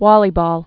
(wôlē-bôl)